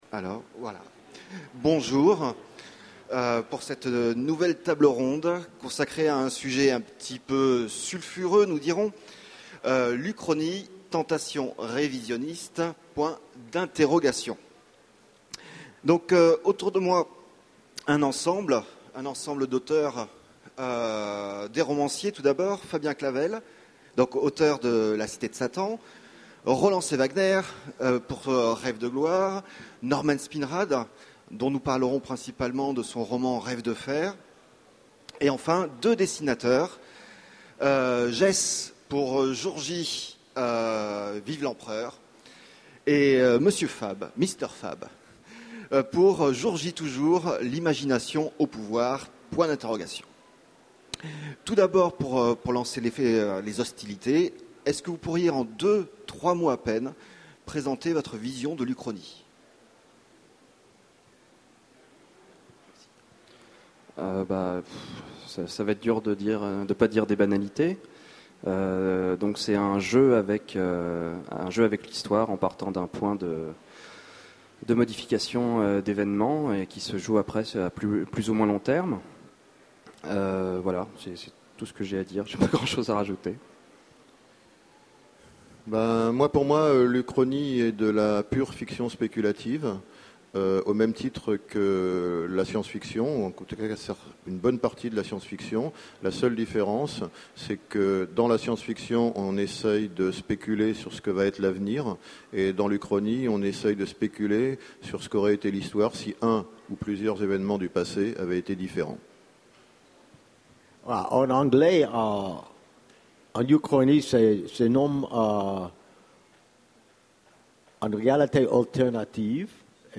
Utopiales 2011 : Conférence Uchronie tentation révisionnistes ?